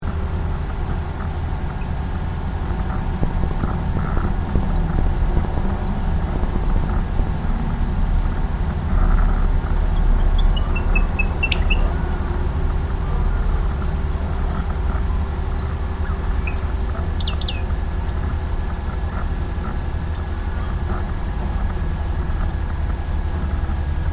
Diucón